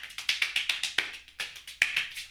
BON108CLAP.wav